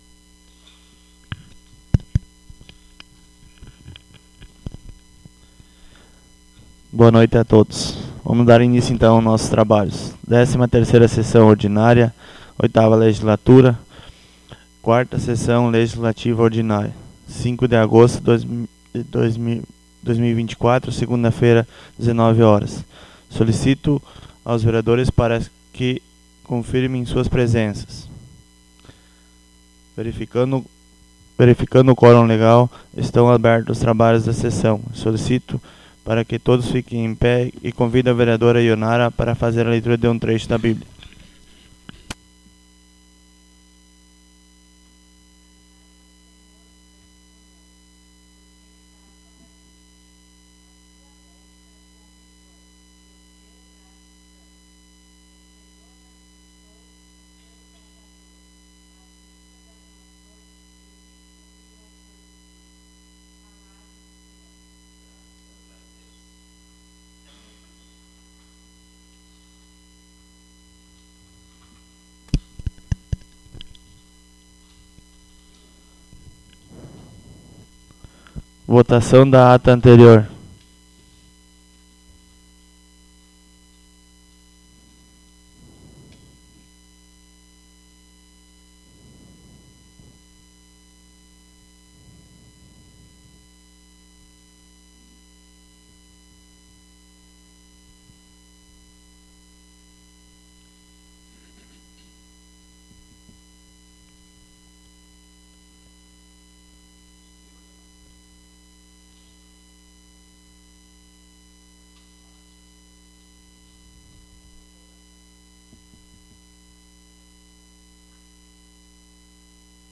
Audio da 13ª Sessão Ordinária 05.08.24